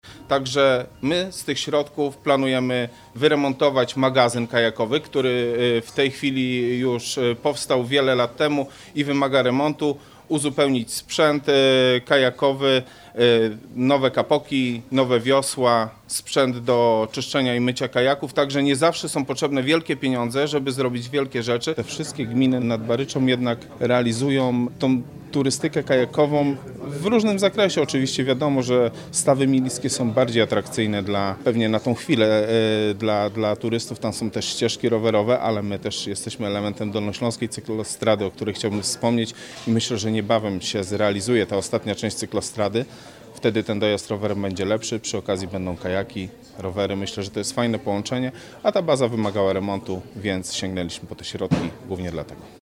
– Dzięki tym środkom możemy poprawić infrastrukturę i zachęcić turystów do korzystania z atrakcji wodnych w naszej gminie – dodaje Paweł Niedźwiedź.